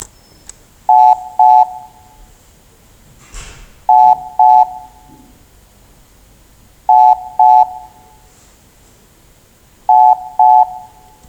【NAKAYO（ナカヨ）ST101A 内線着信音サンプル】
■内線着信音　B